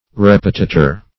Search Result for " repetitor" : The Collaborative International Dictionary of English v.0.48: Repetitor \Rep"e*ti`tor\ (r?p"?-t?`t?r), n. [Cf. L. repetitor a reclaimer.]